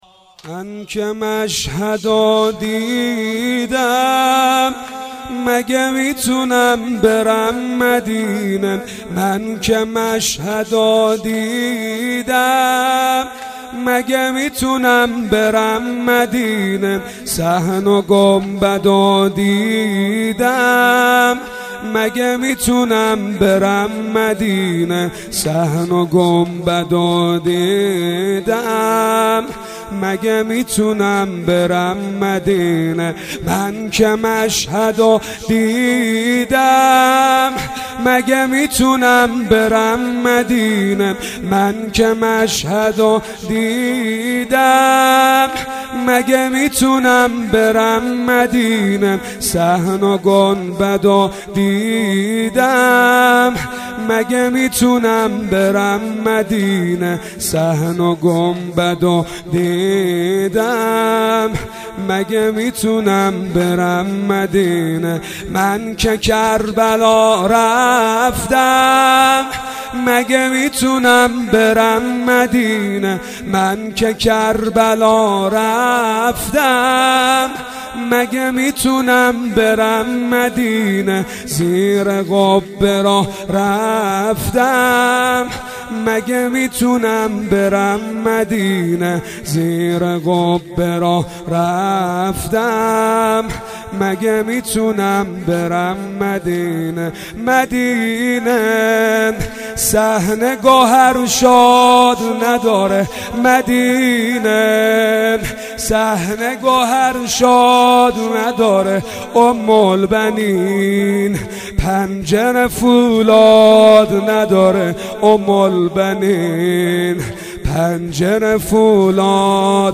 هیئت باب الحوائج نور